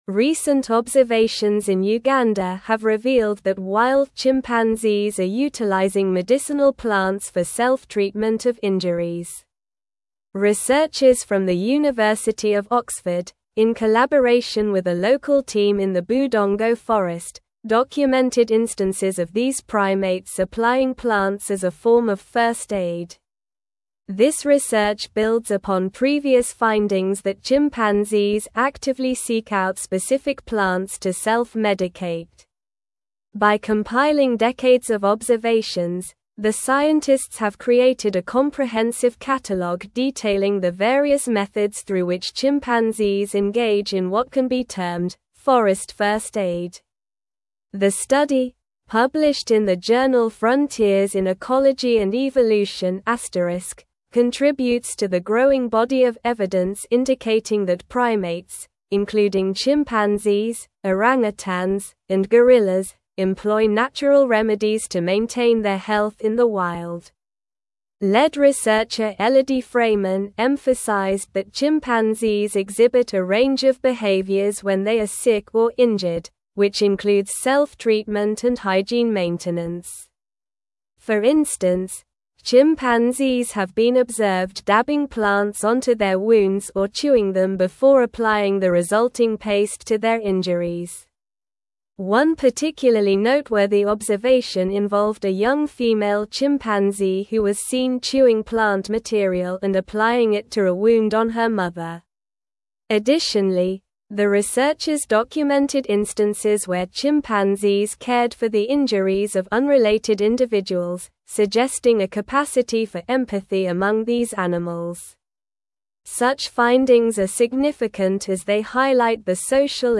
Slow
English-Newsroom-Advanced-SLOW-Reading-Chimpanzees-Use-Medicinal-Plants-for-Self-Care-in-Uganda.mp3